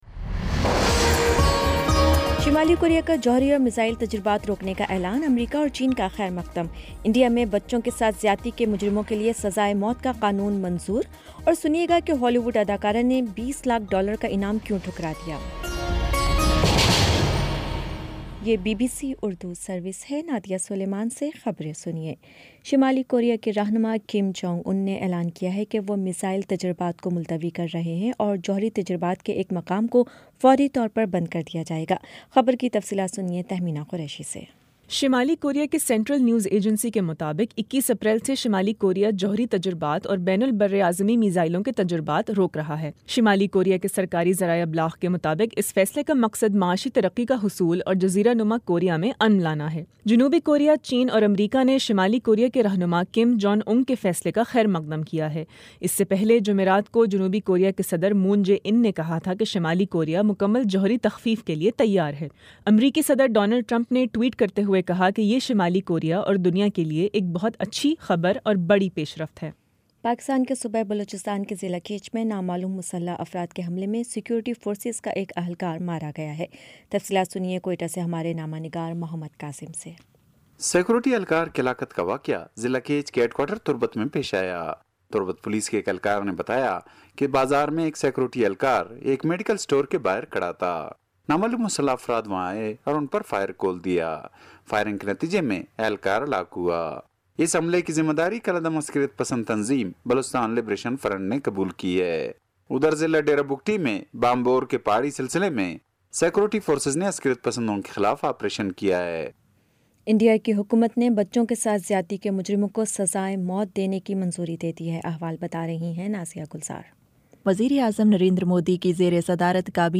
اپریل 21 : شام چھ بجے کا نیوز بُلیٹن